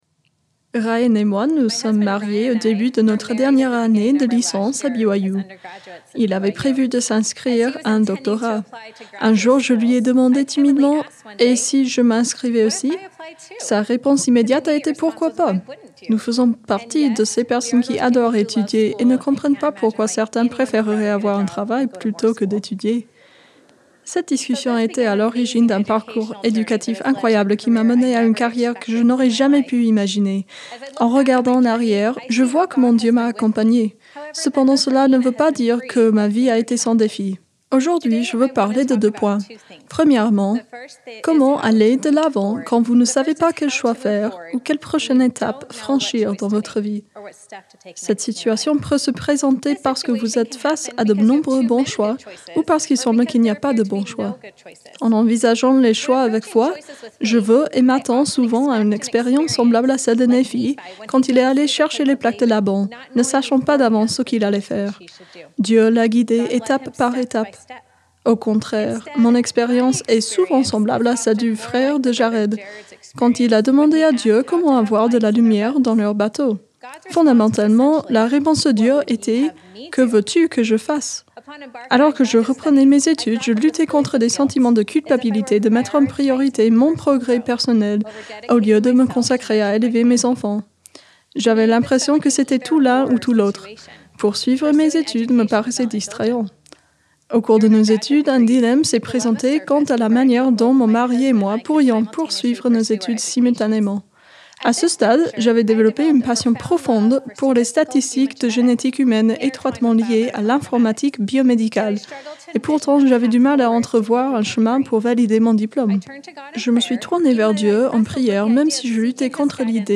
Réunion spirituelle